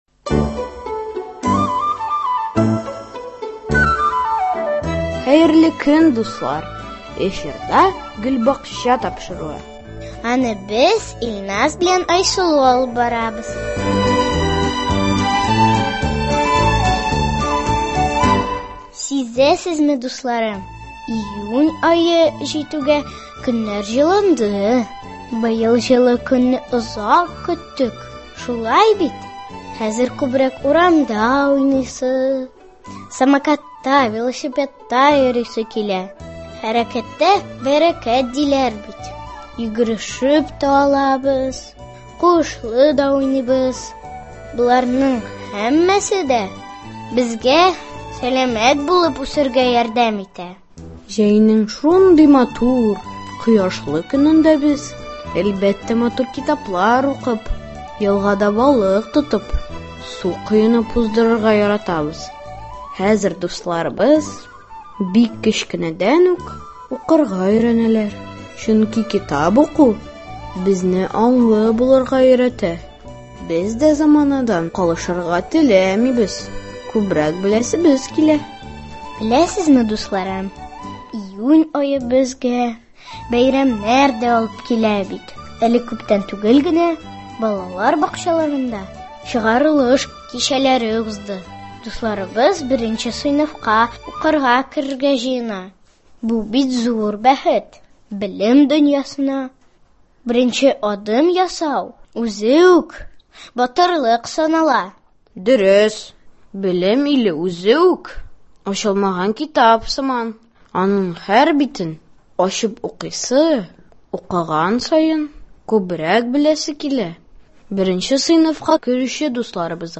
Сезне Сабантуй бәйрәменә чакырабыз. Балалар чыгышы.